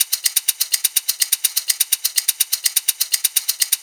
Live Percussion A 18.wav